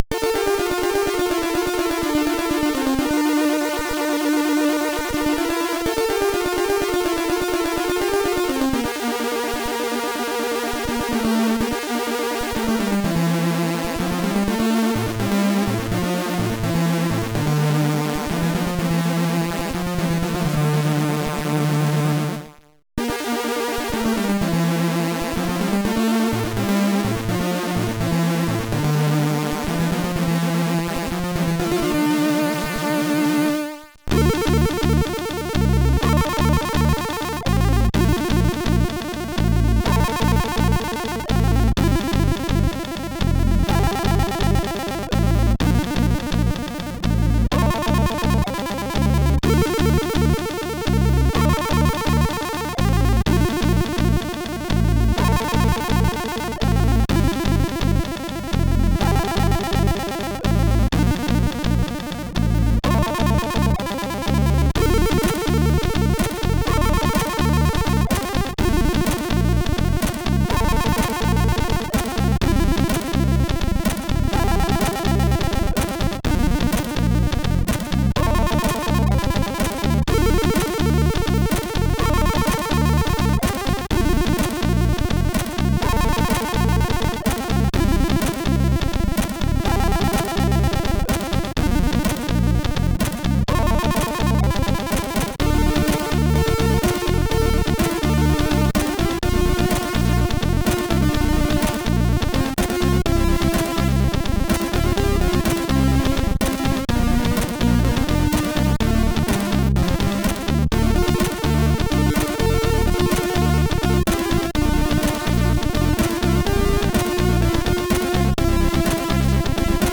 Commodore SID Music File
1 channel